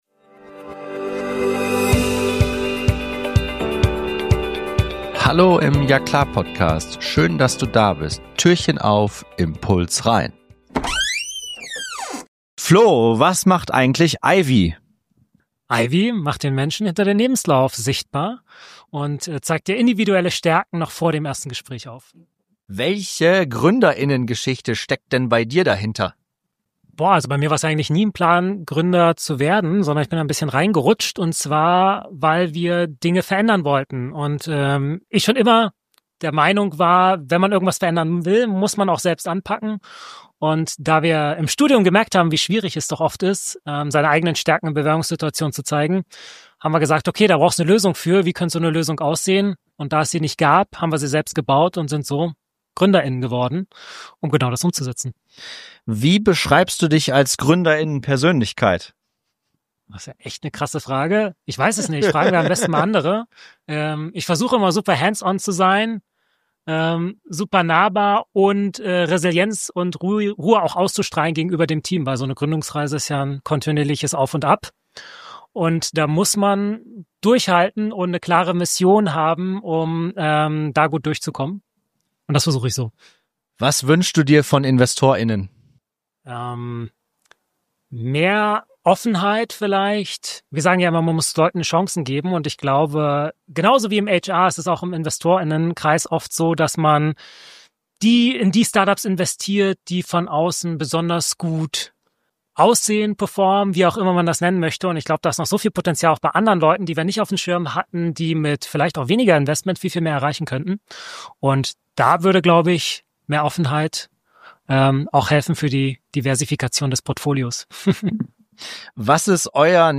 Acht Gründerinnen und Gründer erzählen
in kurzen, ehrlichen Mini-Interviews, was sie antreibt, welches